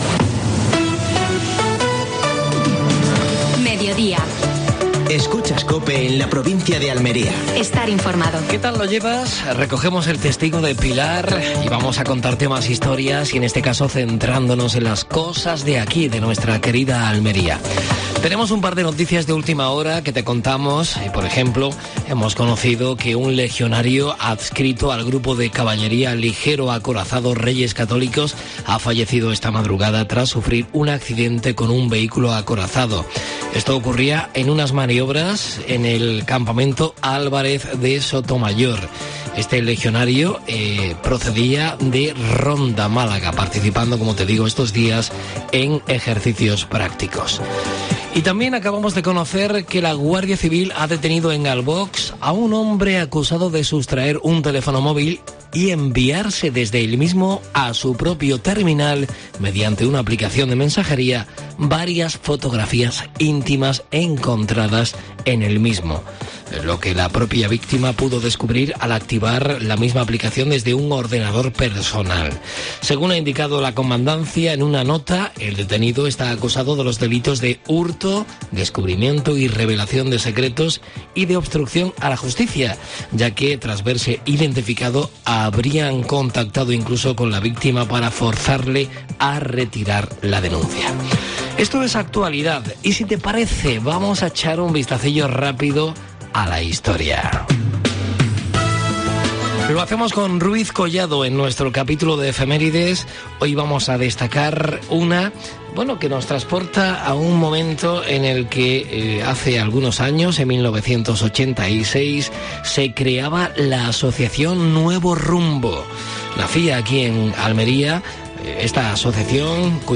Entrevista a María Luisa Cruz (diputada provincial de Almería). Victoria de la UDA ante el Sporting (1-0).